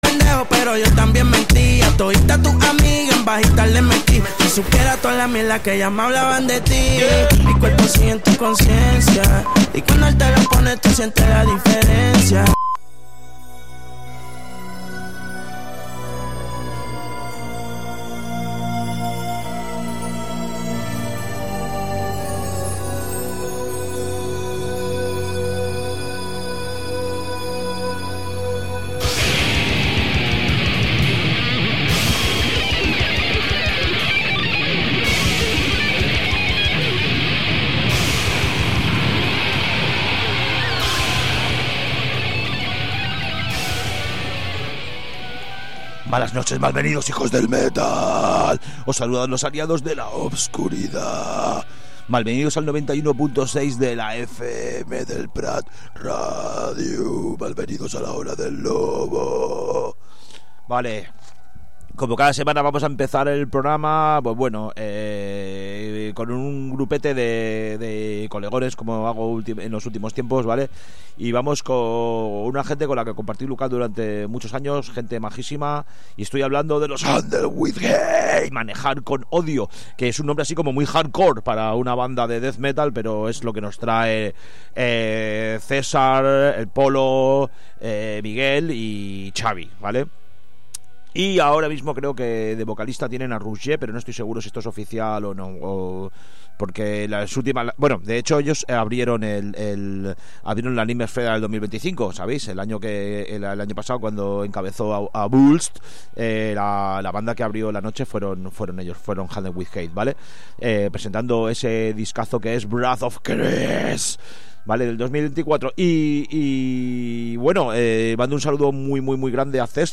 A 'La hora del lobo', ens arrossegarem per tots els subgèneres del metal, submergint-nos especialment en les variants més fosques i extremes.